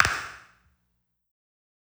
CLAP_LATE.wav